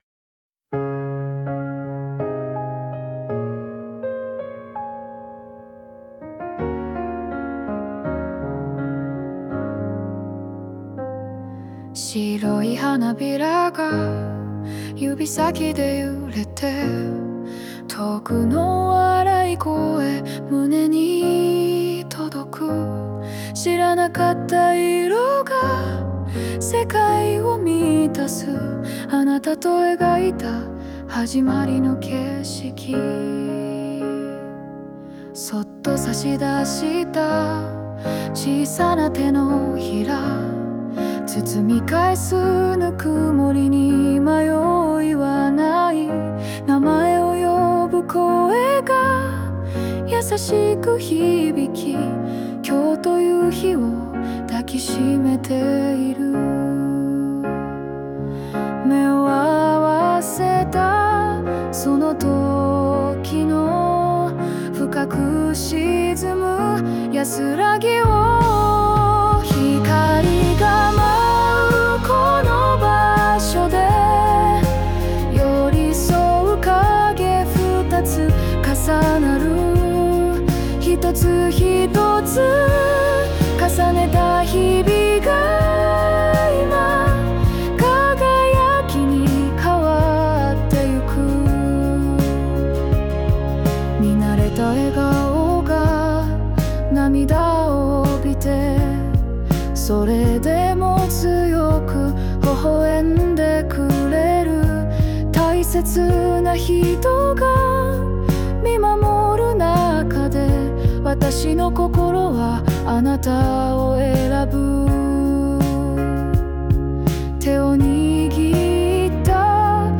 邦楽女性ボーカル著作権フリーBGM ボーカル
著作権フリーオリジナルBGMです。
女性ボーカル（邦楽・日本語）曲です。
結婚式を迎えられた喜びをやさしく描いたウェディングソングです♪